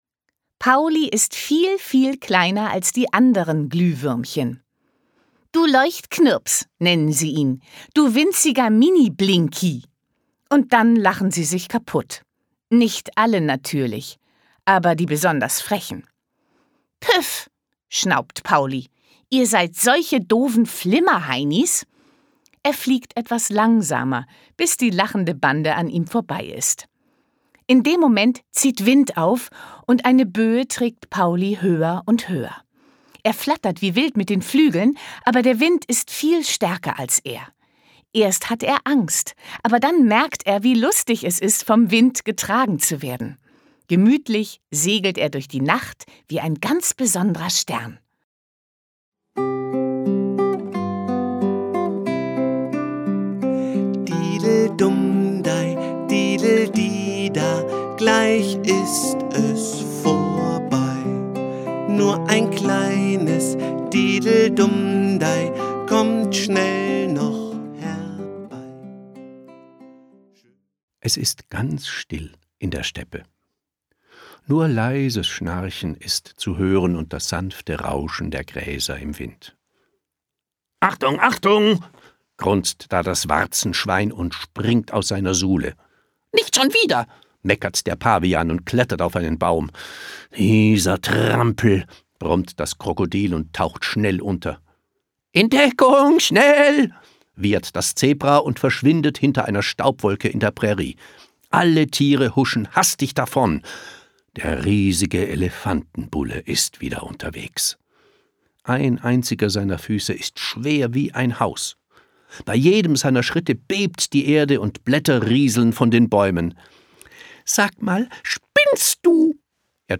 Schlagworte Fantasie • Gutenachtgeschichten • Gute-Nacht-Geschichten • Hörbuch; Lesung für Kinder/Jugendliche • Träume